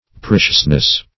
Preciousness \Pre"cious*ness\, n.